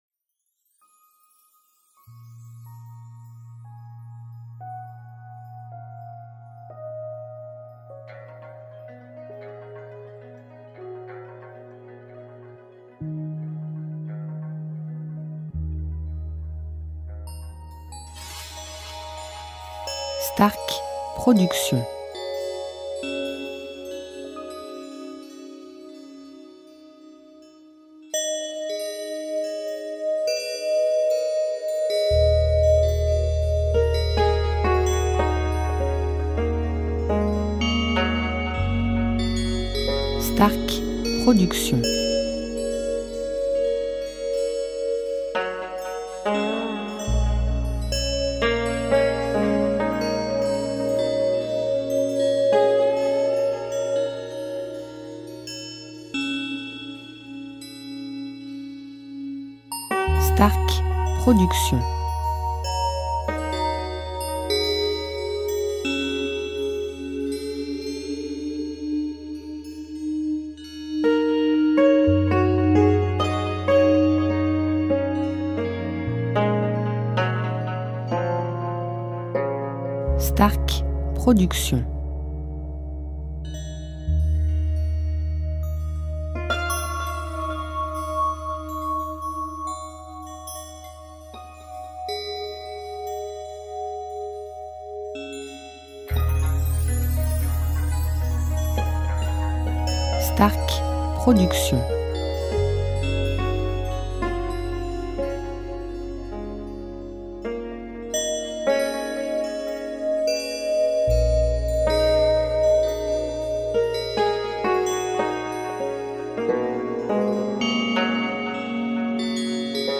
style Californien